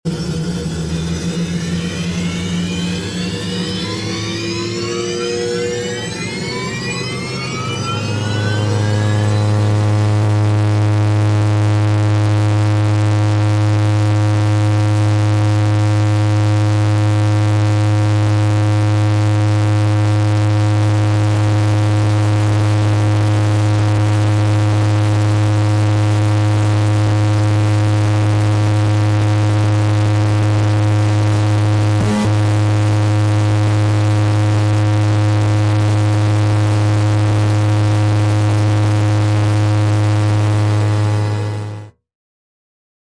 XF 84 Thunderscreech sound ⚠Warning Loud⚠ sound effects free download